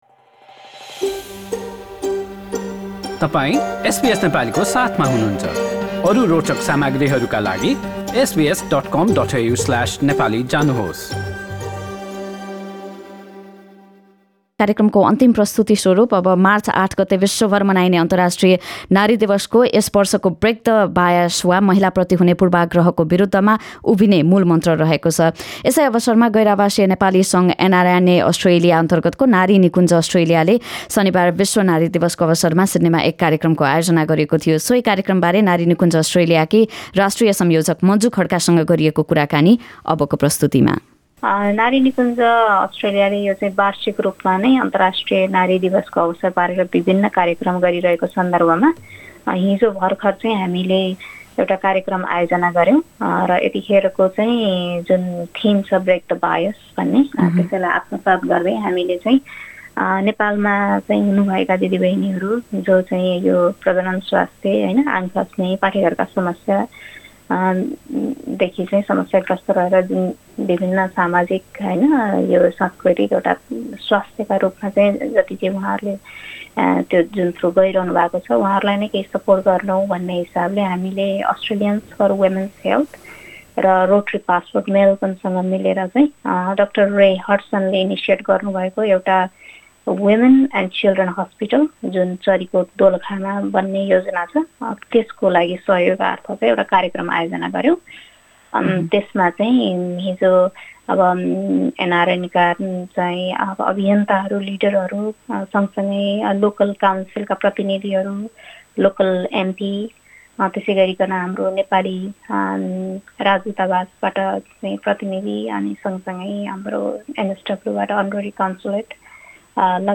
पुरा कुराकानी